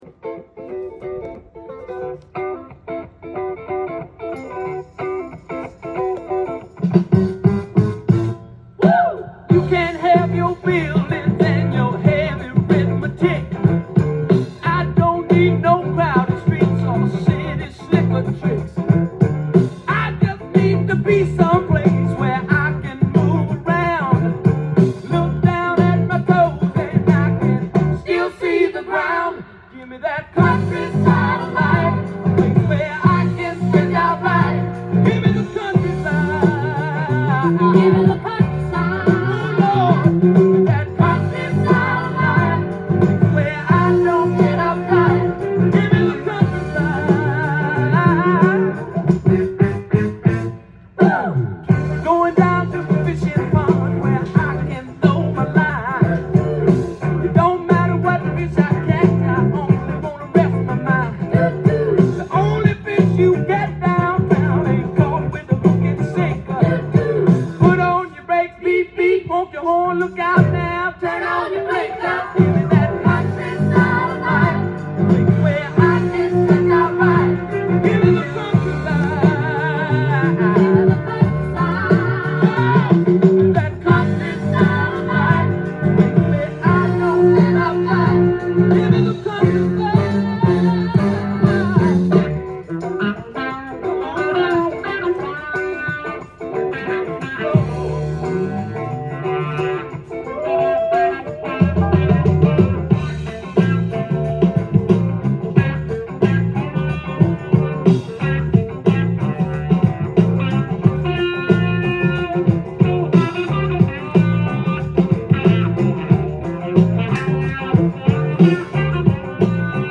ジャンル：ROCK & POPS
店頭で録音した音源の為、多少の外部音や音質の悪さはございますが、サンプルとしてご視聴ください。
この時代のサザンロックは本当かっこいいですね！